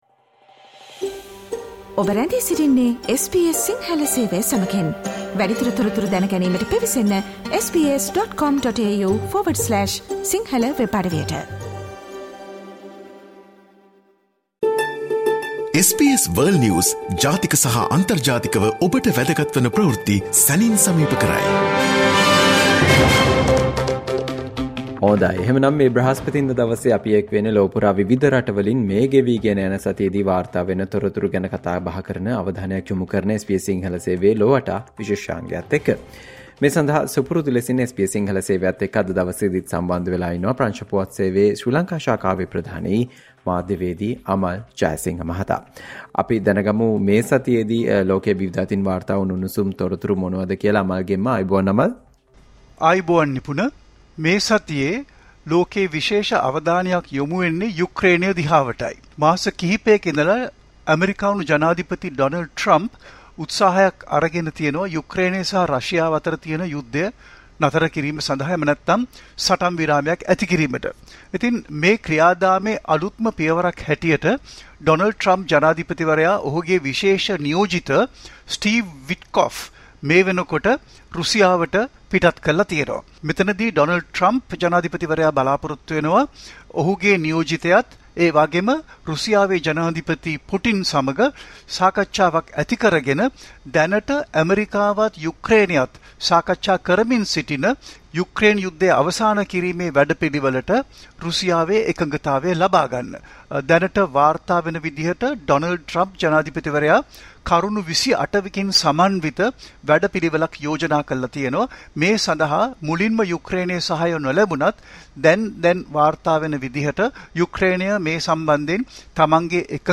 SBS සිංහල සේවය ඔස්සේ ඔබ වෙත ගෙන එන සතියේ විදෙස් පුවත් විග්‍රහය ‘ලොව වටා’ විශේෂාංගය.